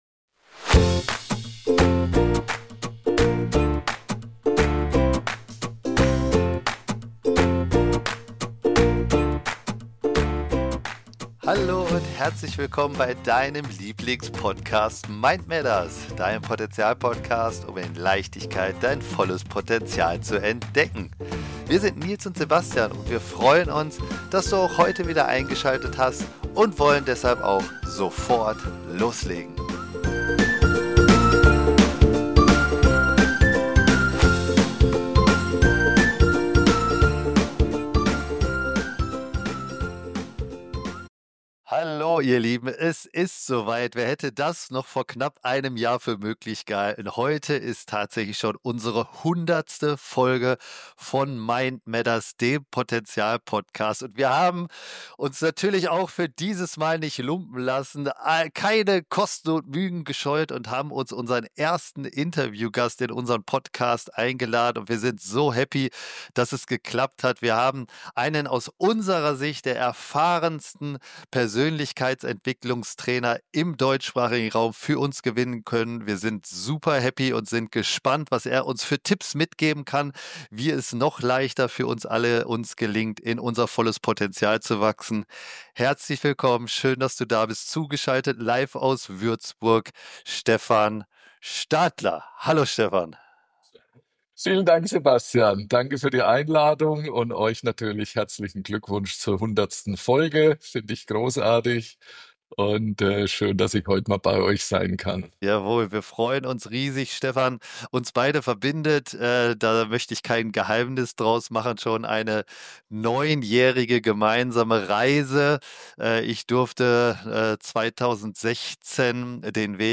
#100 Podcast Special - Interview